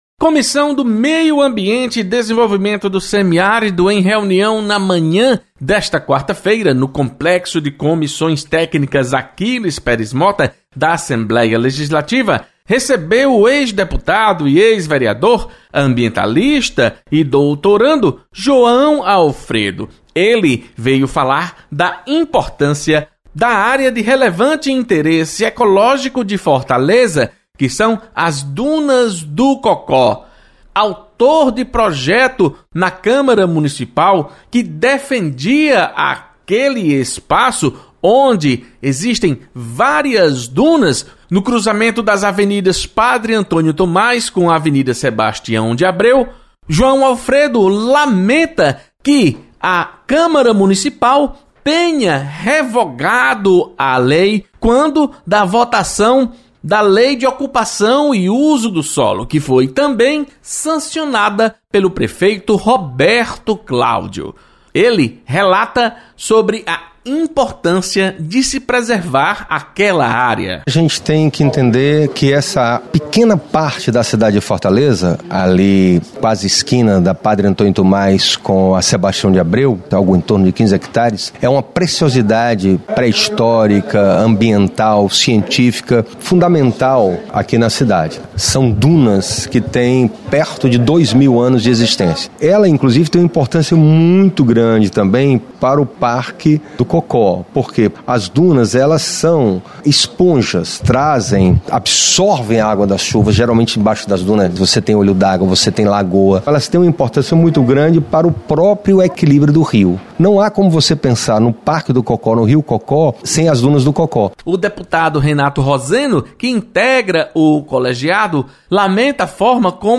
Comissão do Meio Ambiente realiza reunião nesta quarta-feira. Repórter